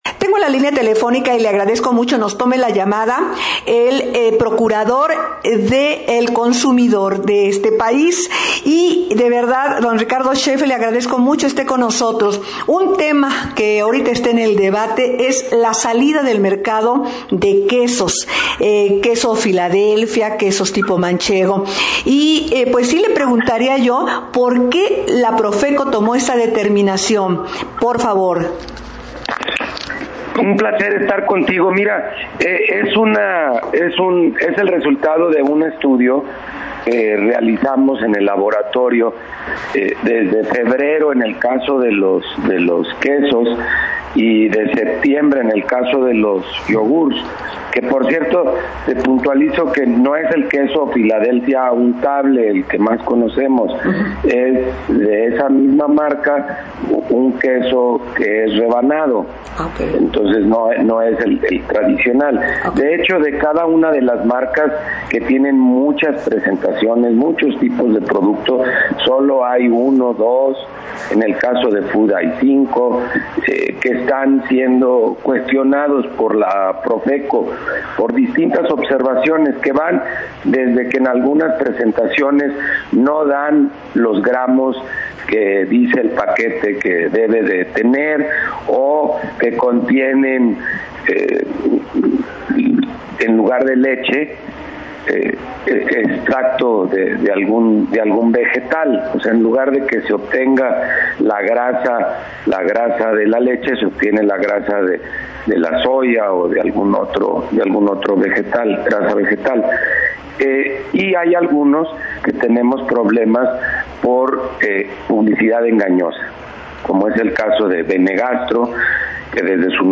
06 ENTREVISTA QUESOS 16 OCT.
06-ENTREVISTA-QUESOS-16-OCT..mp3